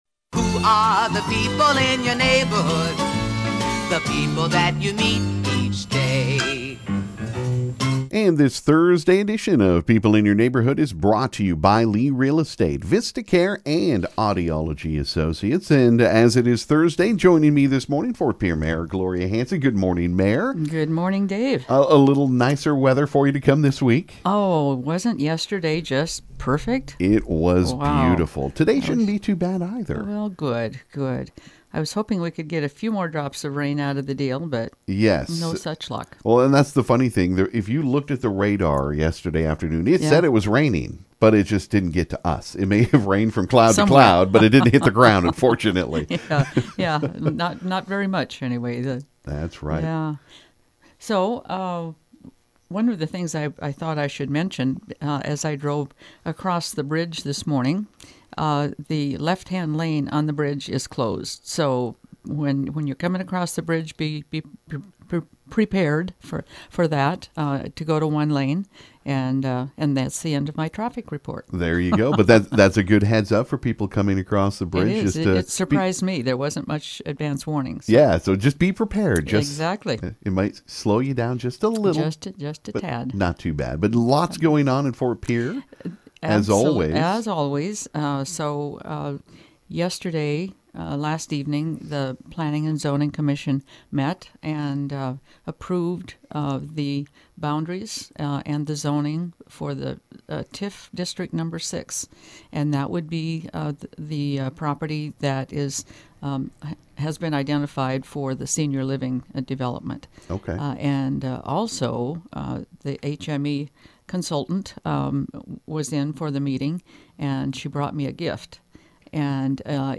This morning Ft. Pierre Mayor Gloria Hanson made her weekly visit to KGFX she shared some information about the Senior Living development looking to build in Ft.